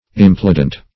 implodent - definition of implodent - synonyms, pronunciation, spelling from Free Dictionary Search Result for " implodent" : The Collaborative International Dictionary of English v.0.48: Implodent \Im*plod"ent\, n. (Phon.) An implosive sound.